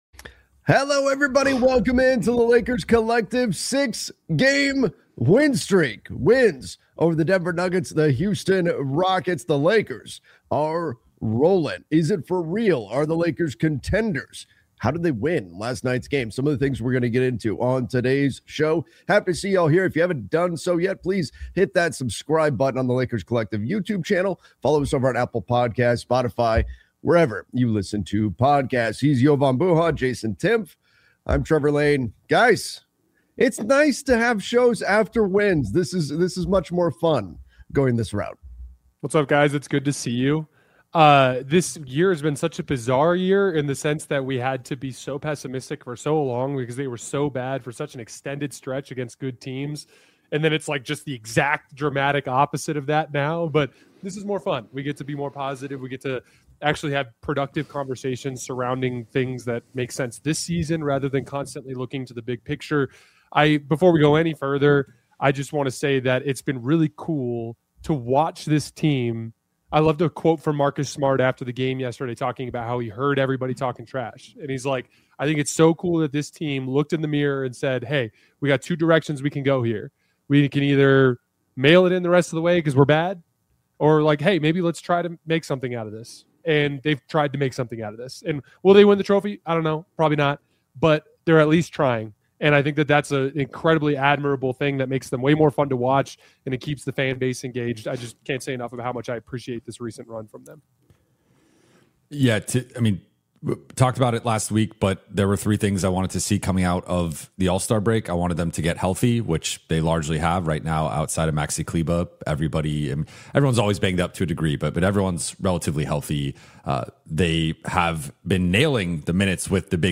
a weekly Los Angeles Lakers roundtable